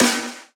• Trap Snare Sound B Key 190.wav
Royality free acoustic snare sample tuned to the B note.
trap-snare-sound-b-key-190-rhX.wav